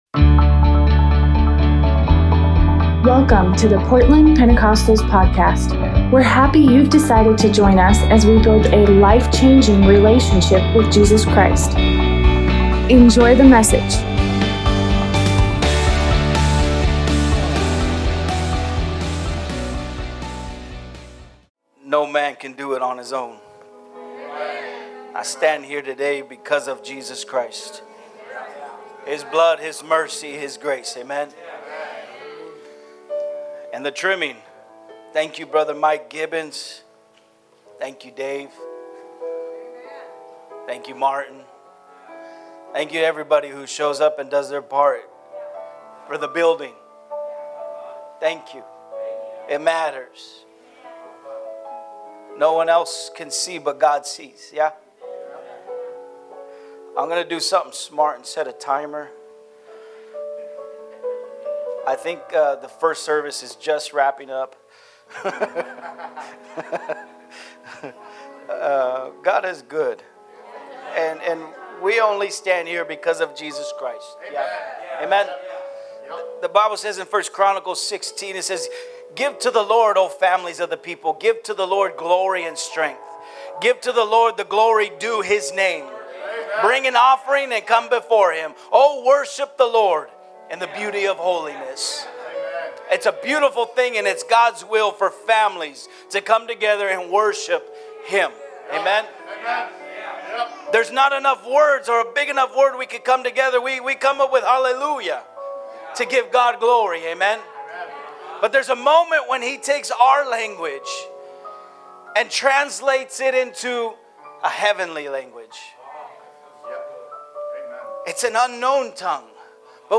Father’s Day service